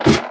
ladder5.ogg